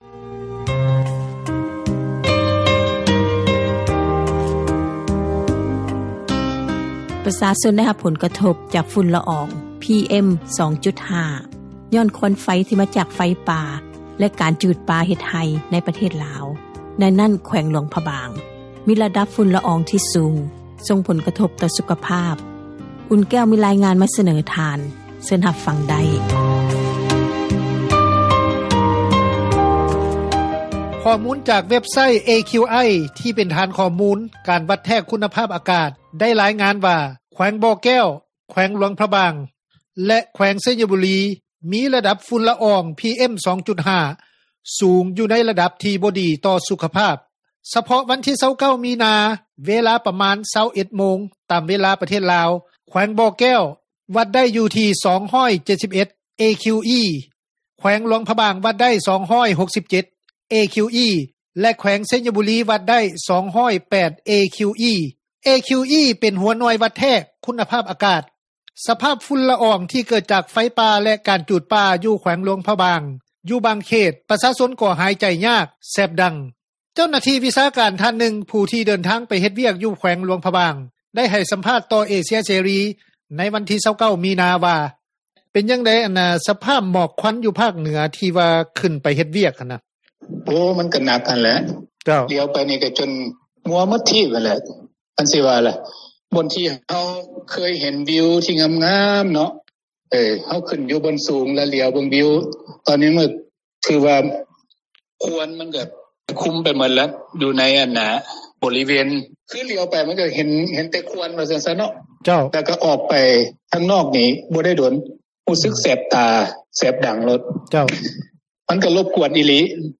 ສະພາບຝຸ່ນລະອອງ ທີ່ເກີດຈາກໄຟປ່າ ແລະ ການຈູດປ່າ ຢູ່ແຂວງຫລວງຣະບາງ ຢູ່ບາເຂດ ປະຊາຊົນ ກໍຫາຍໃຈຍາກ ແສບດັງ. ເຈົ້າໜ້າທີ່ວິຊາການທ່ານນຶ່ງ ຜູ້ທີ່ ເດີນທາງໄປເຮັດວຽກ ຢູ່ແຂວງຫລວງຣະບາງ ໄດ້ໃຫ້ສັມພາດຕໍ່ວິທຍຸ ເອເຊັຽເສຣີ ໃນວັນທີ 29 ມີນາ.